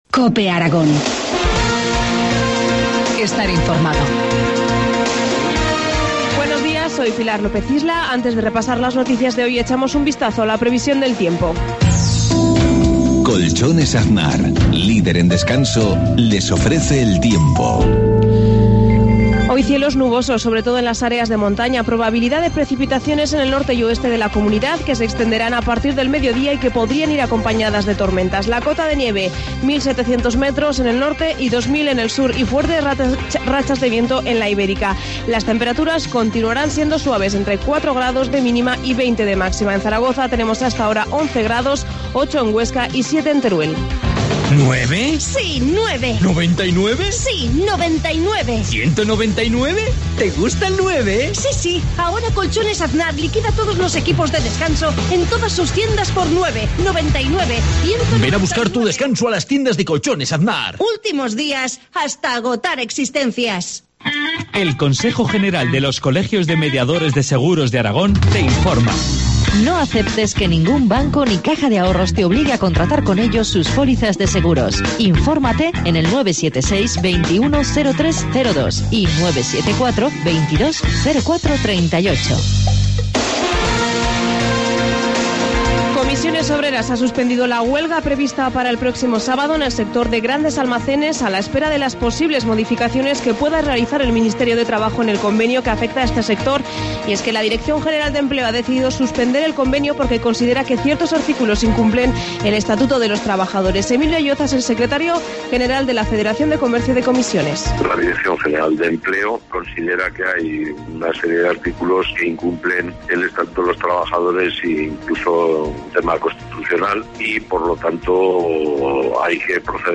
Informativo matinal, martes 26 de marzo, 7.53 horas